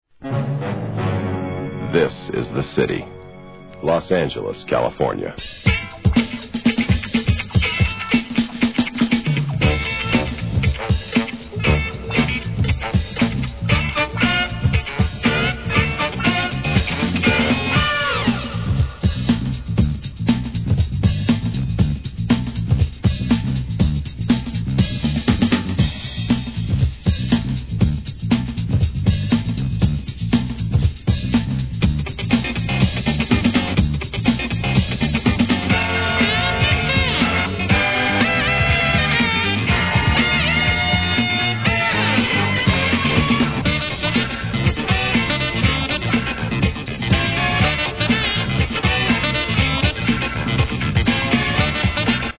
HIP HOP/R&B
激アツOLD SCHOOL!!